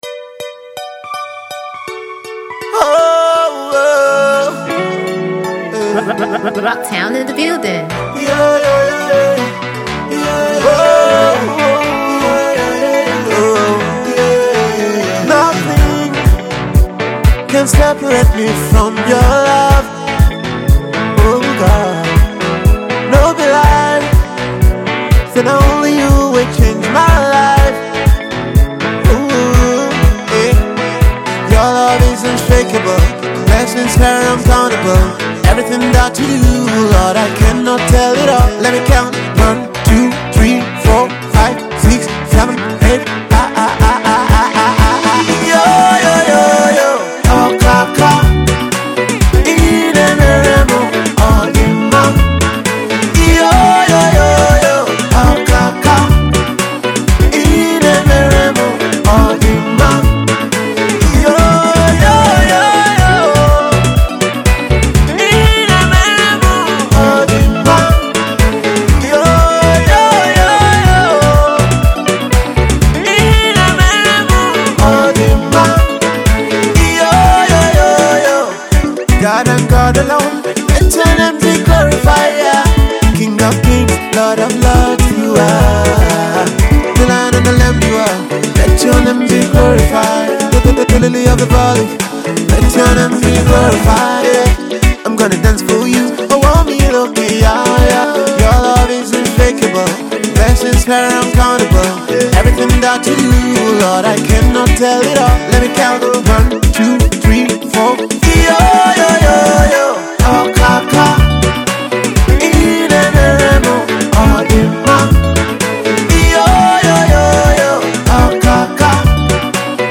an afro highlife flavoured tune